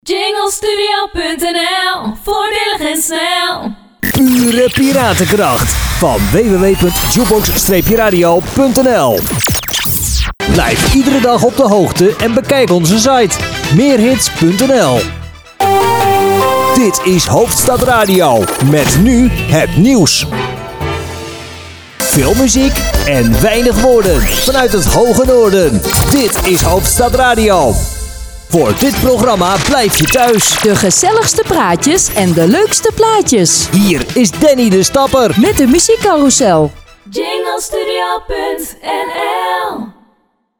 voorbeeld Mannenstem:
Demo-Mannenstem.mp3